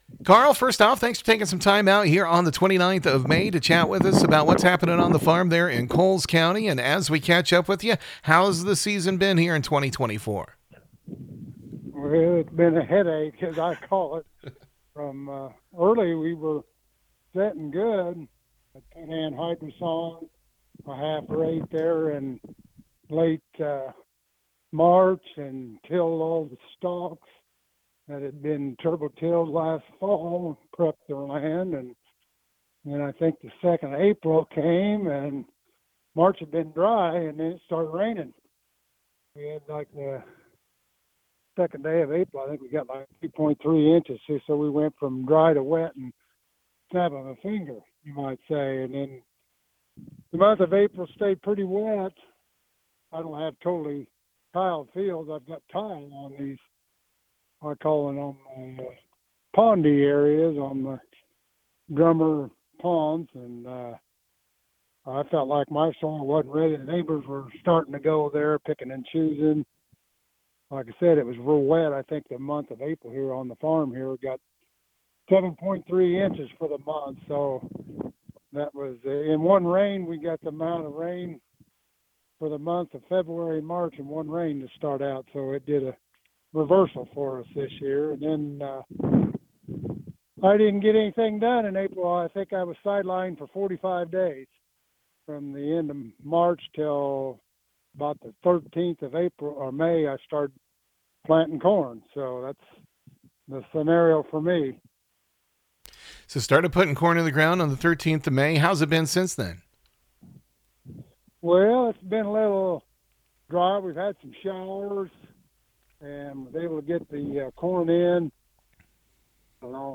A southeastern Illinois farmer says it’s been a rough spring.